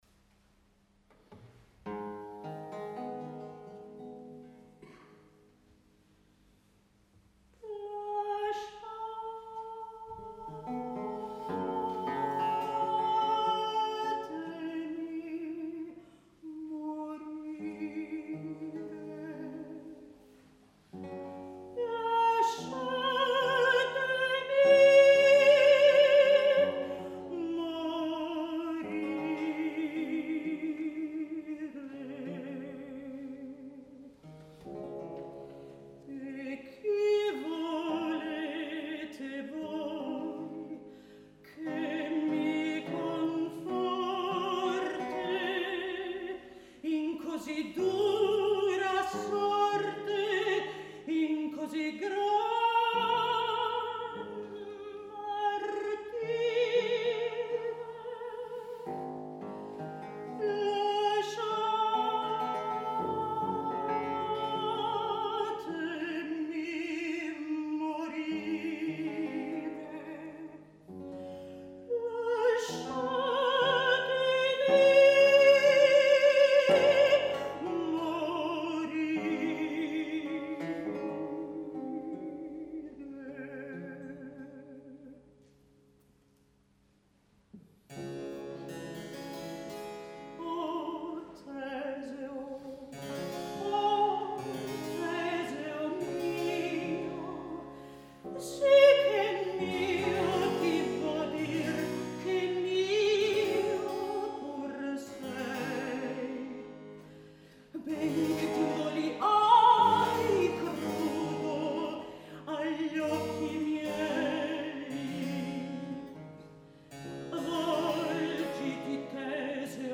Venue: St. Brendan’s Church
Instrumentation Category:Small Mixed Ensemble Instrumentation Other: Mez-solo, vc, thb, hpd
harpsichord
theorbo/archlute
recorder/cello
mezzo-soprano